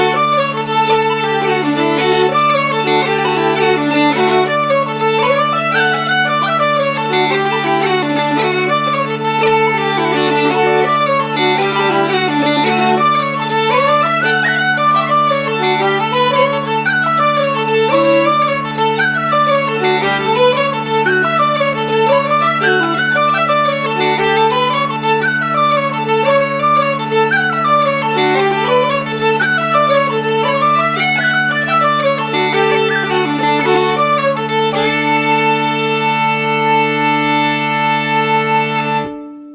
Uillean piper